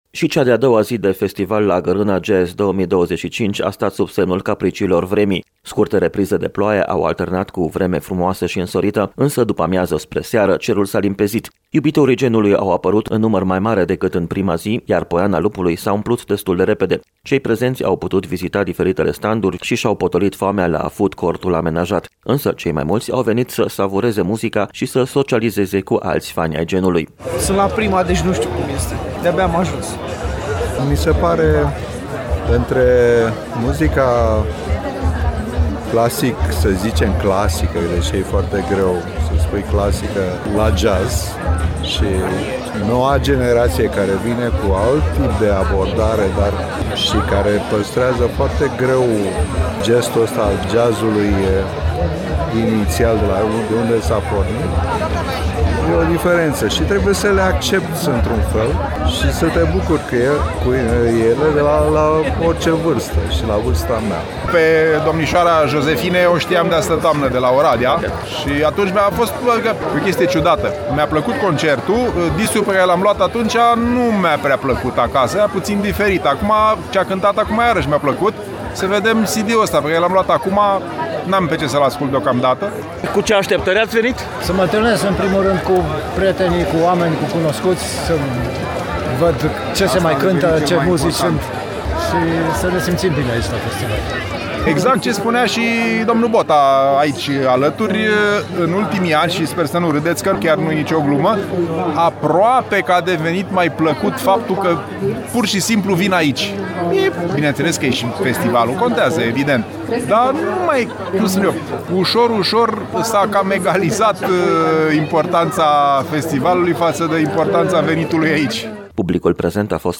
Ediţia 2025 a Festivalului Gărâna Jazz a continuat, aseară, în Poiana Lupului, la poalele Muntelui Semenic.